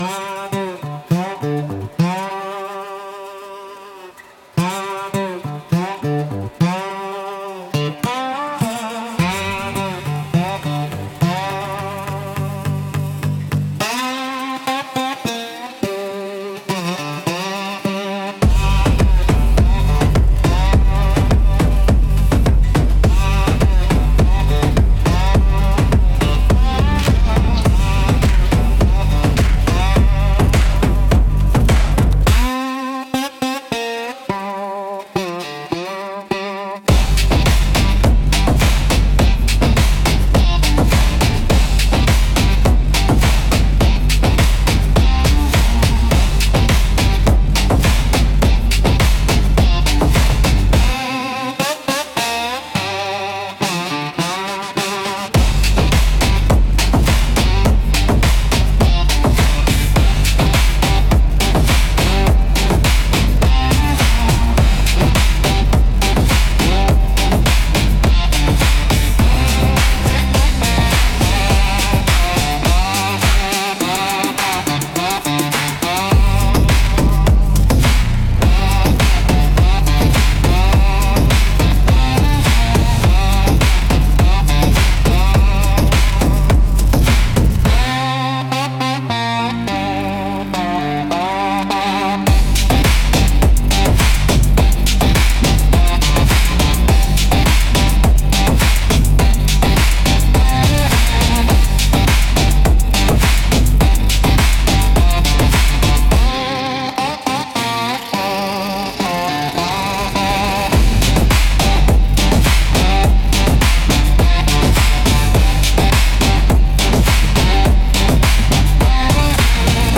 Instrumental -Kiss the Warning Bells 3.31